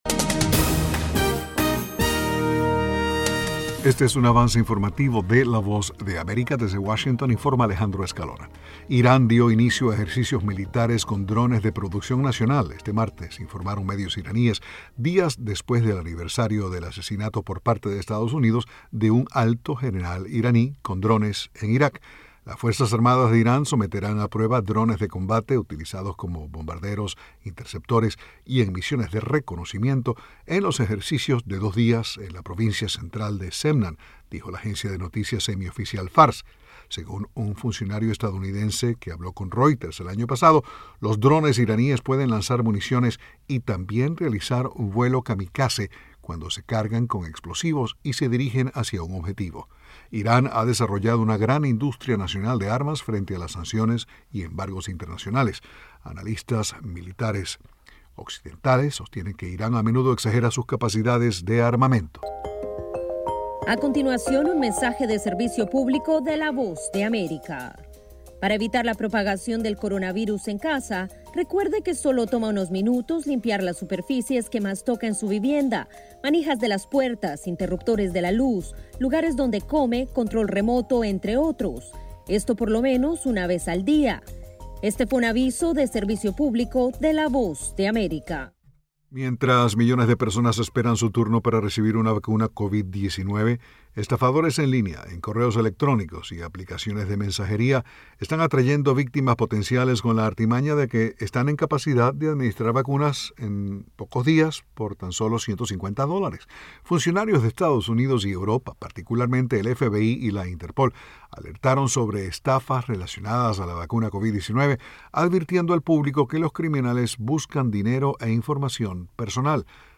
Avance Informativo 11:00 am